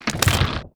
GORE_SPLATS
SPLAT_Crunch_Crack_03_mono.wav